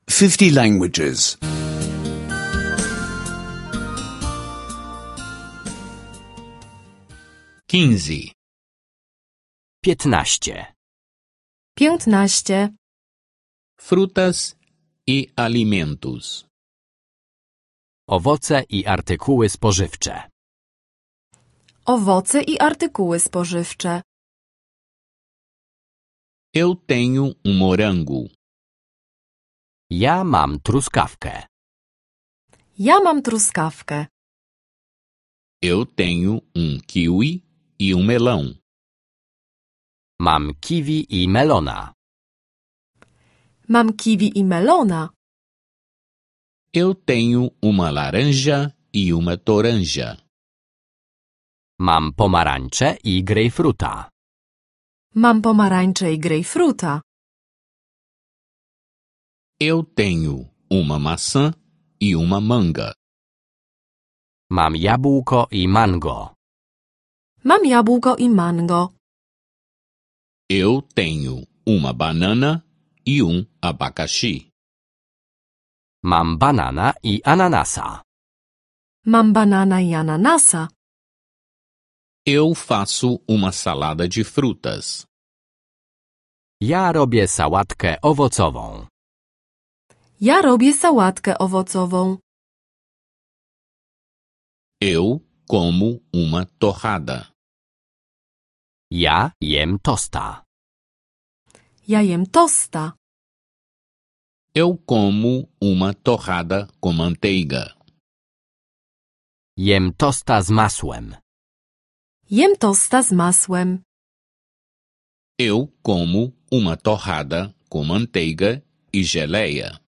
Aulas de polonês em áudio — download grátis